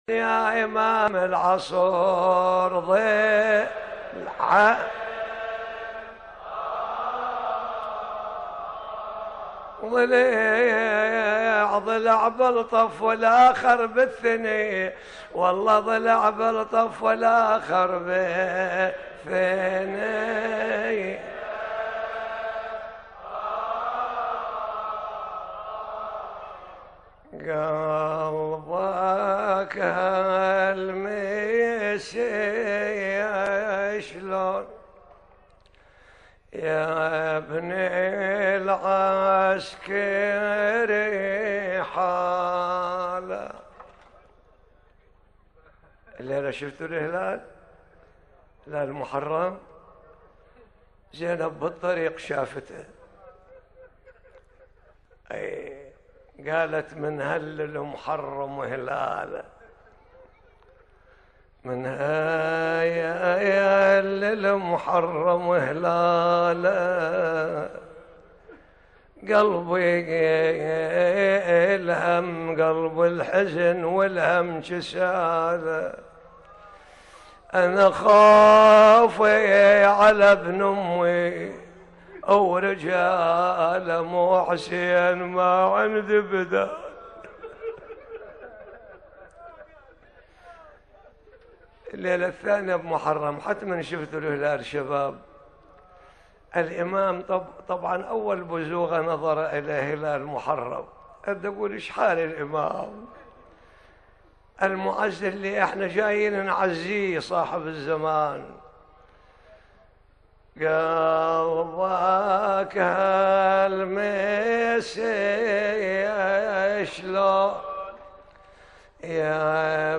ليلة 2 محرم 1437 هـ البصرة
السيد-جاسم-الطويرجاوي-الكربلائي-ليلة-2-محرم-1437-هـ-البصرة.mp3